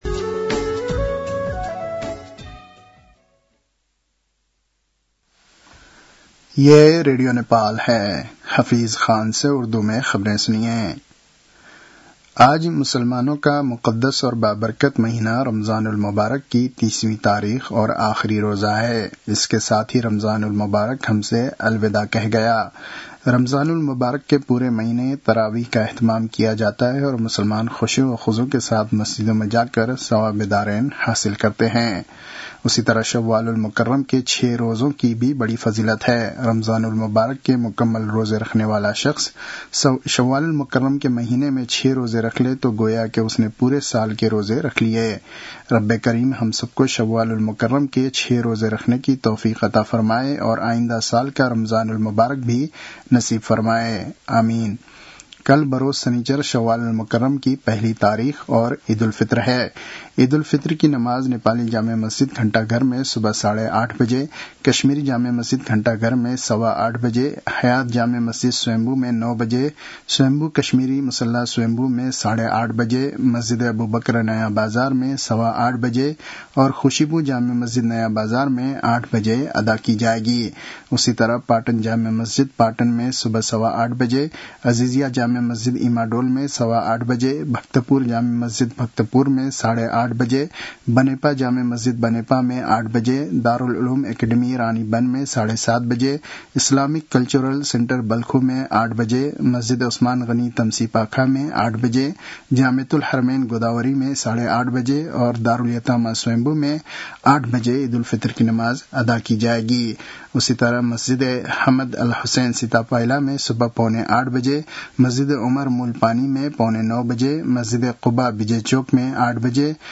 उर्दु भाषामा समाचार : ६ चैत , २०८२
Urdu-news-12-06.mp3